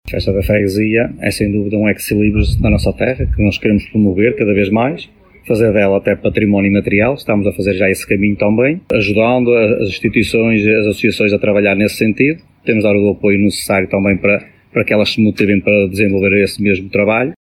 Já o presidente da Junta de Freguesia, Cristiano Ferreira, destaca a importância desta Festa das Cruzes em Serzedelo, avançando que há o objetivo de que sejam reconhecidas como património imaterial.